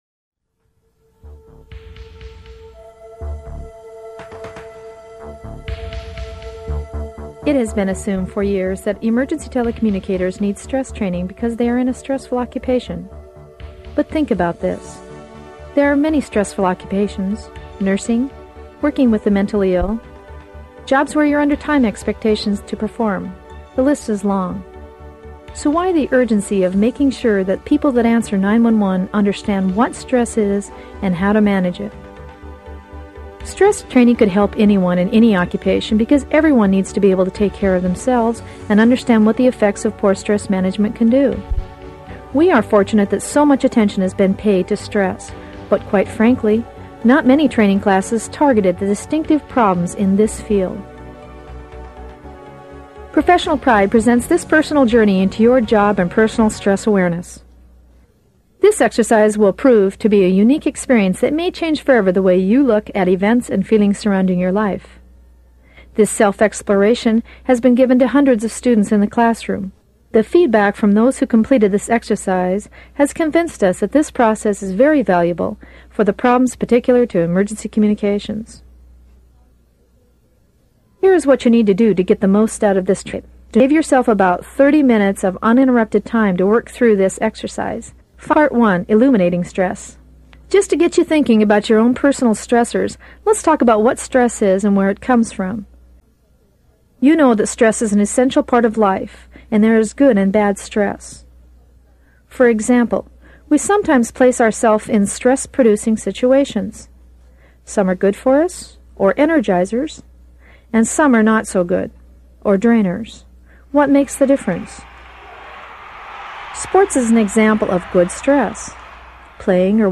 With a simple beep, you can seamlessly transition to surprising and enlightening activities that provoke thought and awakening.
Audio and book fit together for a full in-service workshop on stress. The audio beeps tell you when to turn the page and do the exercise.